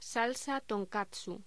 Locución: Salsa tonkatsu
locución
Sonidos: Voz humana